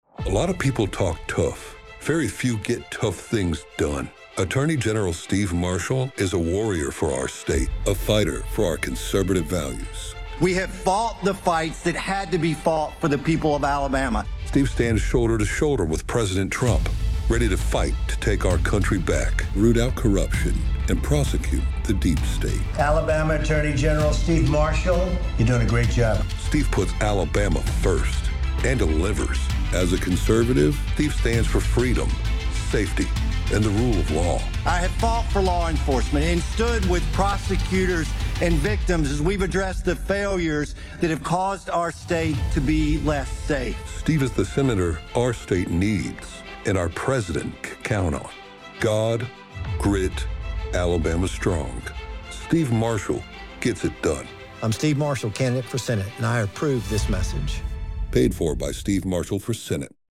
If you listen to the radio in Alabama, you’ll likely soon hear a lot of Alabama U.S. Senate candidate Steve Marshall talking about his conservative record.
In the next part of the ad, you can hear President Donald Trump praising Marshall.